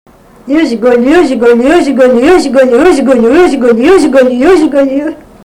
vokalinis
smulkieji žanrai